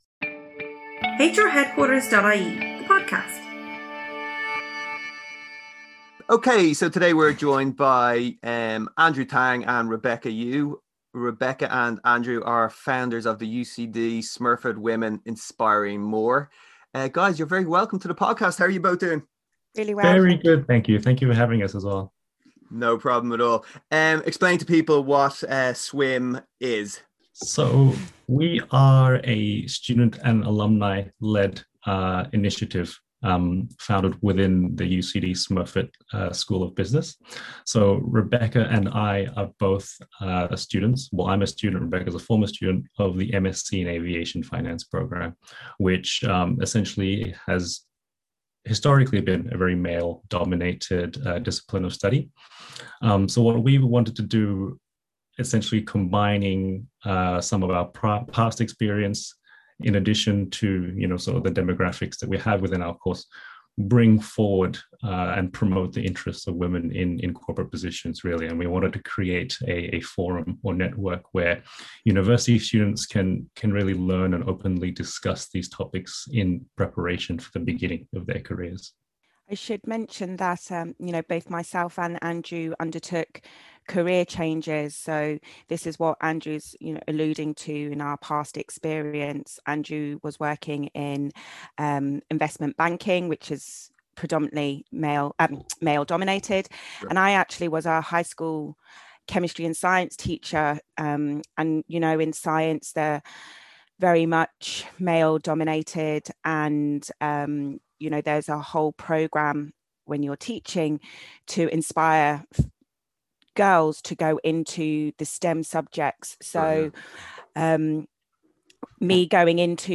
The Interview Series